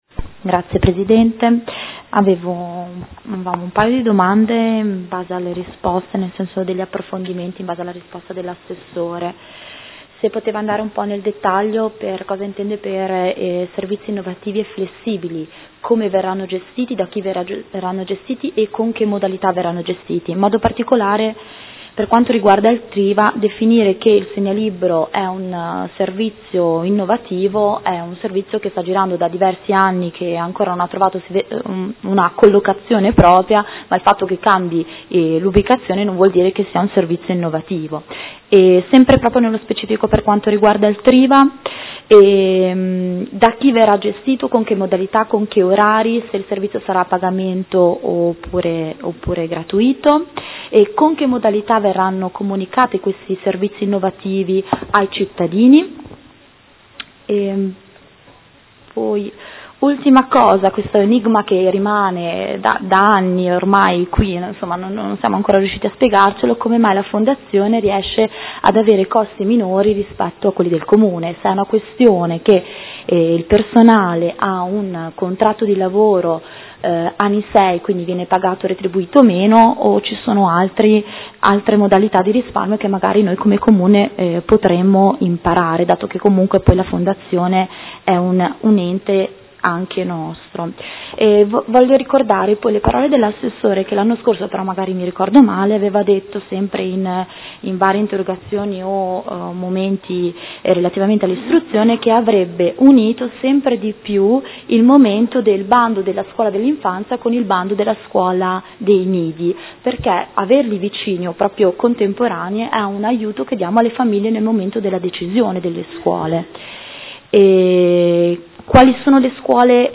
Seduta del 14/04/2015 Dibattito. Interrogazione del Consigliere Rocco (FaS-S.I.) avente per oggetto: Bando comunale servizio nidi e Interrogazione del Gruppo Consiliare Per Me Modena avente per oggetto: Qual è la situazione dei nidi a Modena?